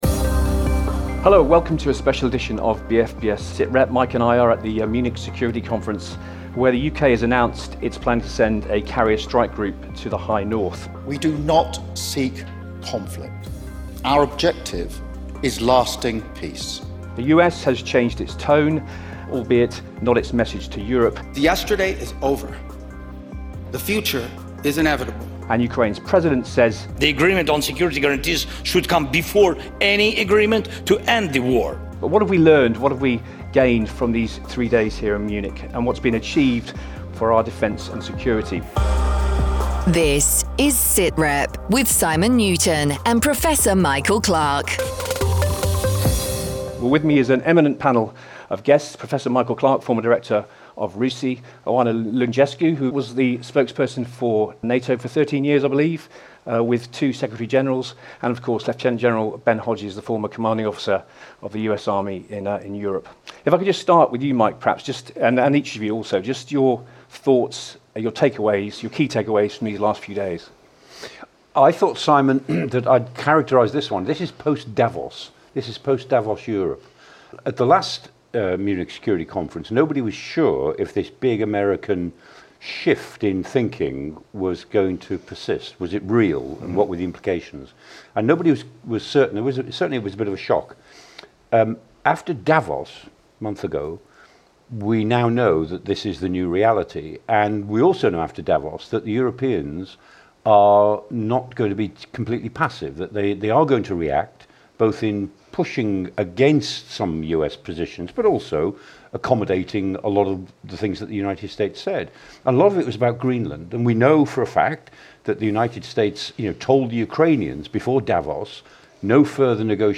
Award winning Sitrep brings you discussion and analysis on defence, foreign policy and the stories affecting the British Forces. Presented by Kate Gerbeau, with expert analysis from Professor Michael Clarke.